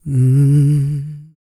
E-SOUL 303.wav